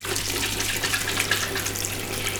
agua.wav